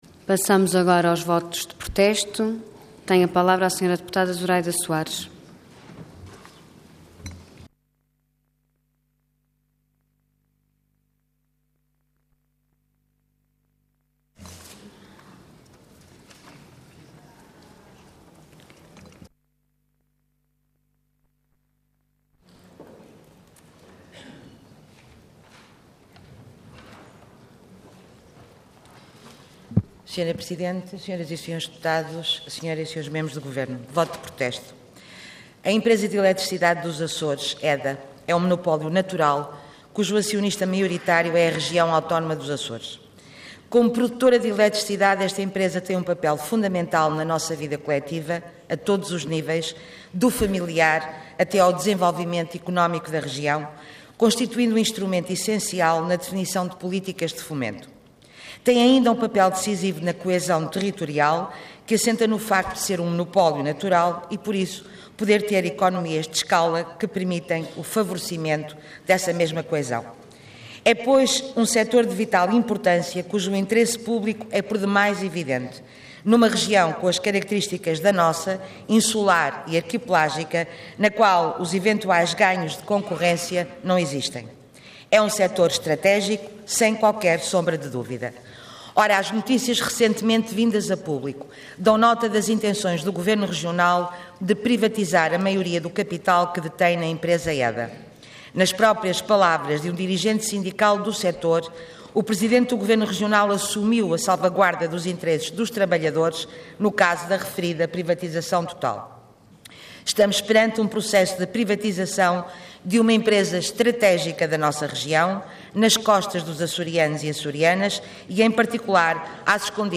Intervenção Voto de Protesto Orador Zuraida Soares Cargo Deputada Entidade BE